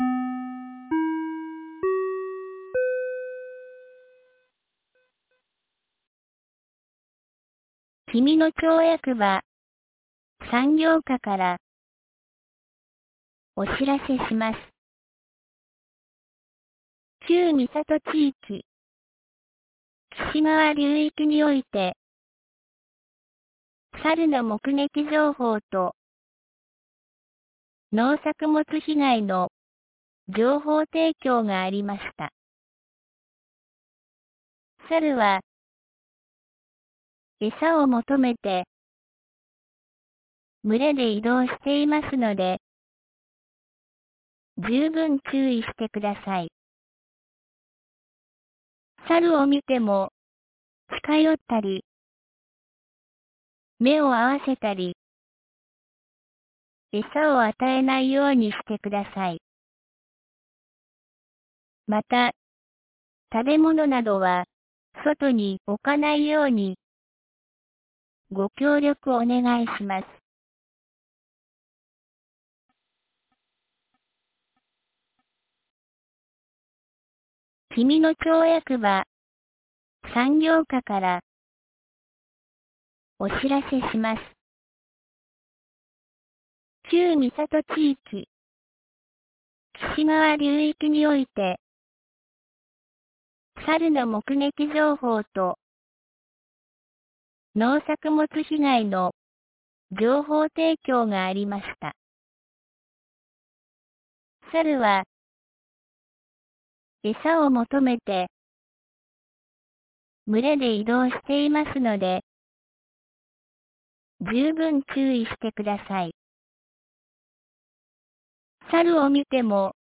2025年08月03日 12時37分に、紀美野町より全地区へ放送がありました。